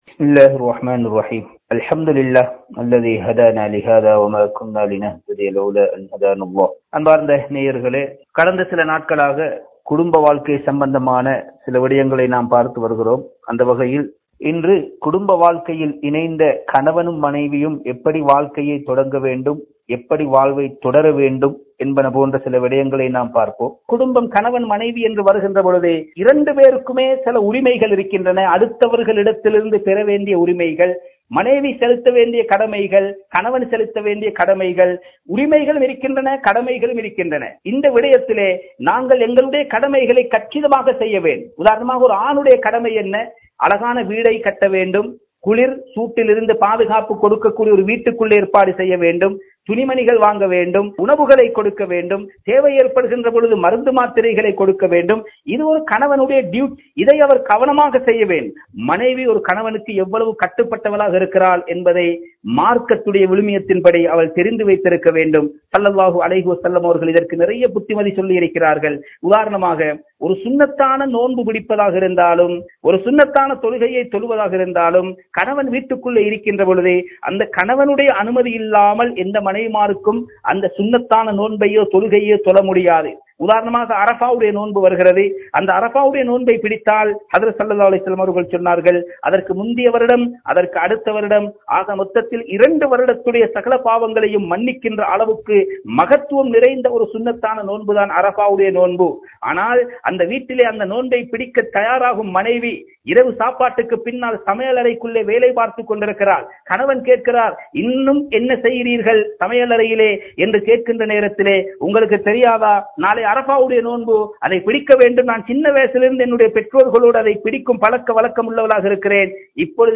Bayans